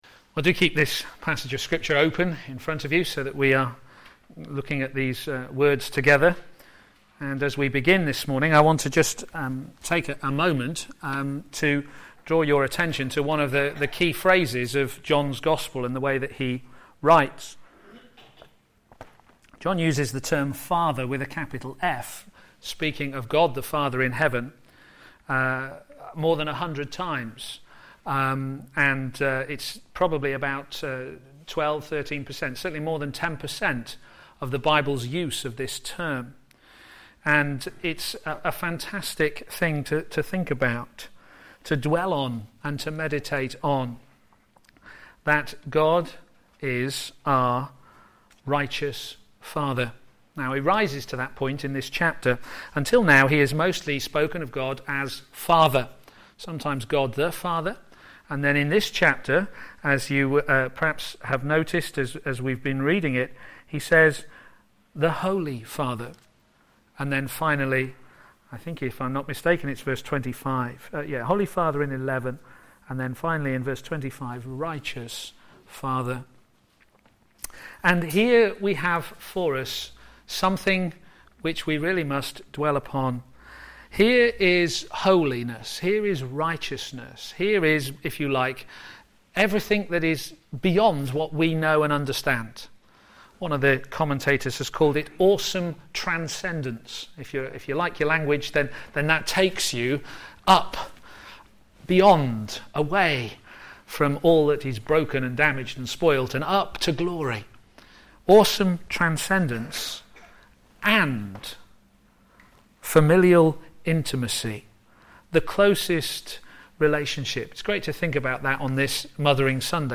Series: John on Jesus Theme: Jesus prays for us Sermon